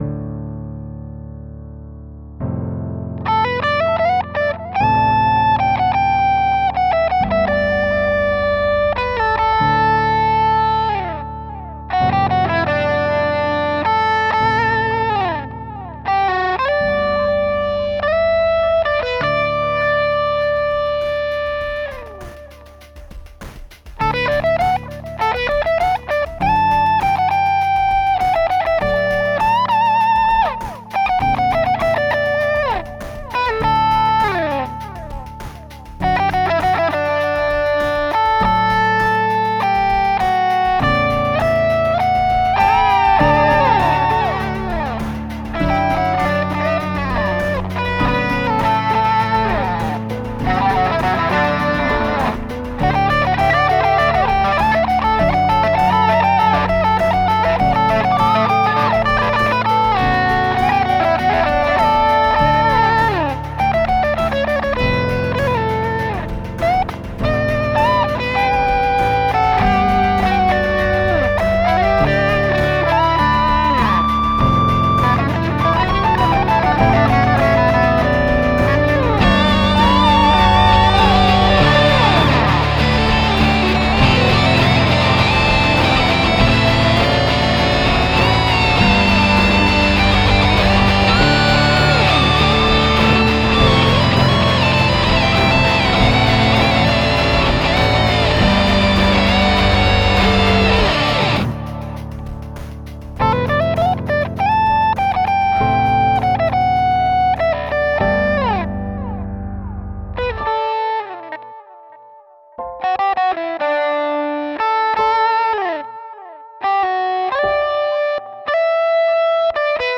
tone poem